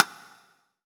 SNARE 106.wav